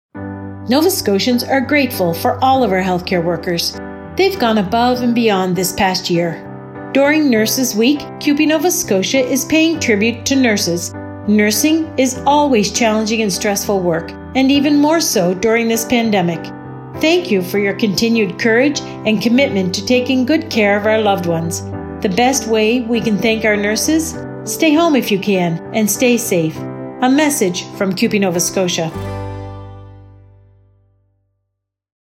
As well, a radio ad will run on stations province-wide.